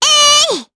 Estelle-Vox_Attack2_jp.wav